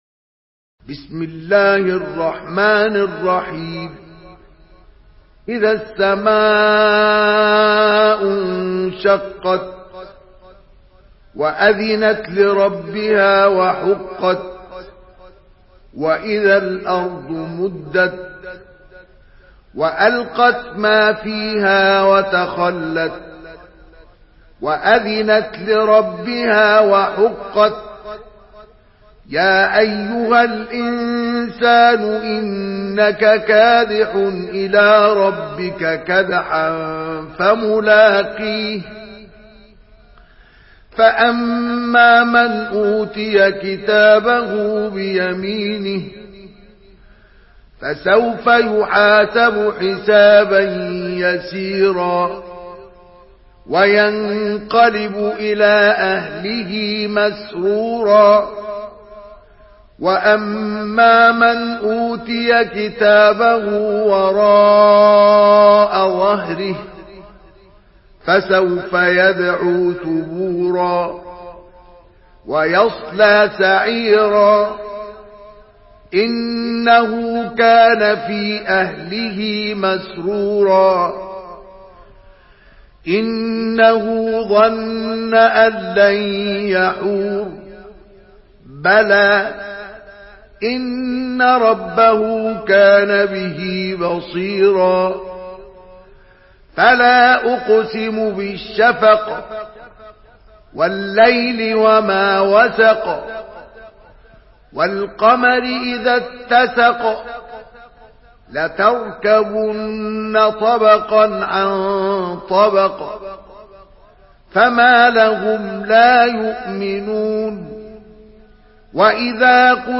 سورة الانشقاق MP3 بصوت مصطفى إسماعيل برواية حفص
مرتل